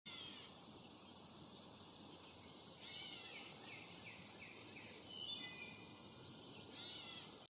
Grey Catbird birdsounds of summer sound effects free download
Grey Catbird birdsounds of summer with Northern Cardinal and Wood Thrush in background